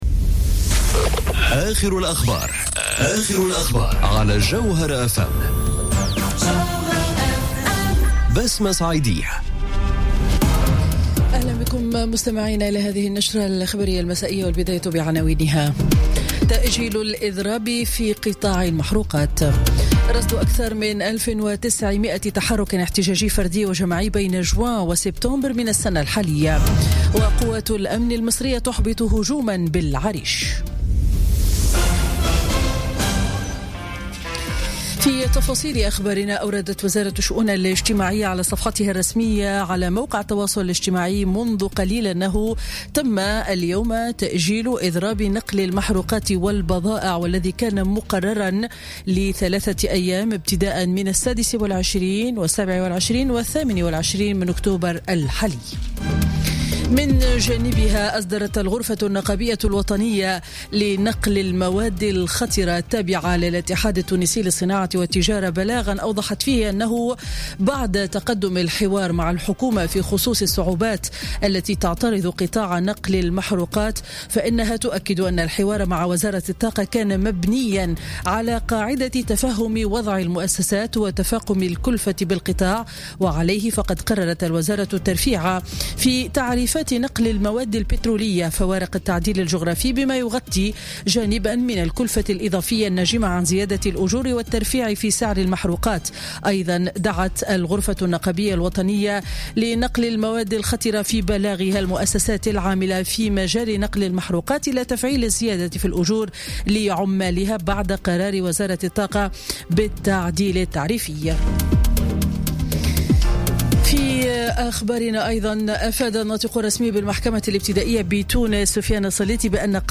نشرة أخبار السابعة مساء ليوم الأربعاء 25 أكتوبر 2017